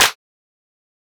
Clap Groovin 4.wav